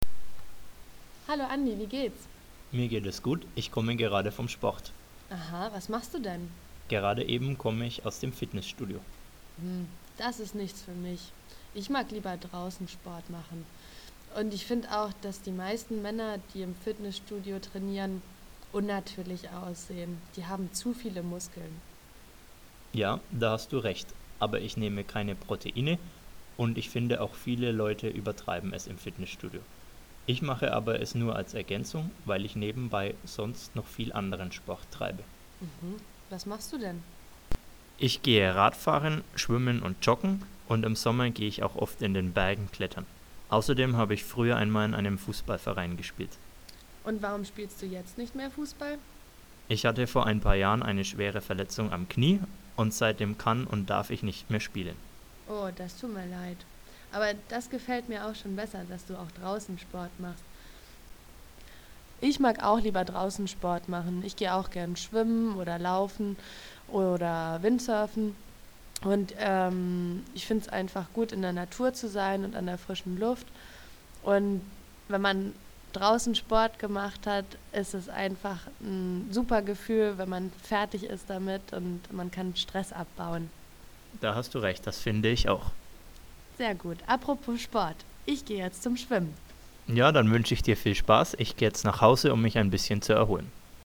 Accent
Allemand
Deux jeunes parlent de sport.